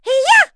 Laudia-Vox_Attack3.wav